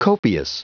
Prononciation du mot copious en anglais (fichier audio)
Prononciation du mot : copious